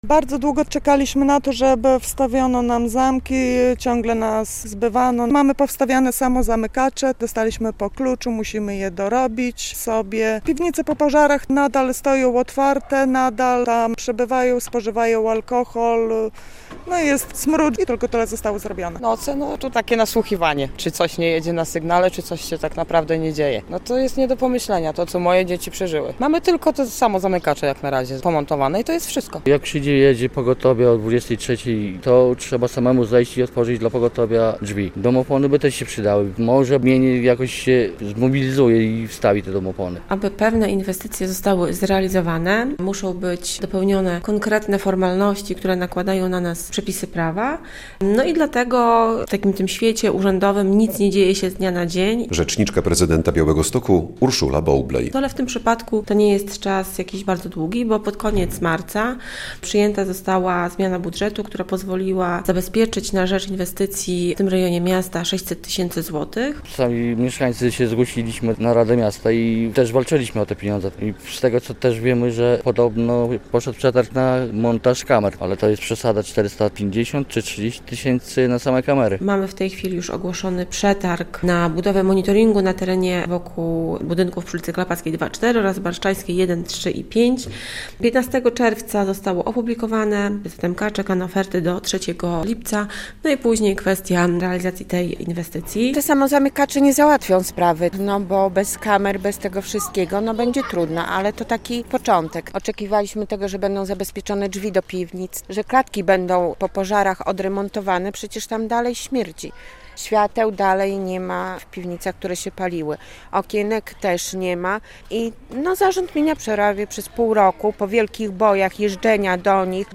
Mieszkańcy bloków przy ul. Barszczańskiej i Klepackiej nadal nie czują się bezpiecznie - relacja